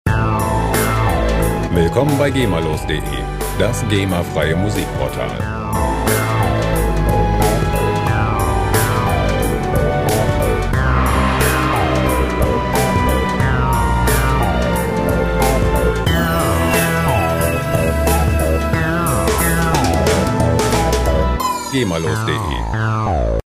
gema-freie Hip-Hop Loops
Musikstil: Hip-Hop
Tempo: 90 bpm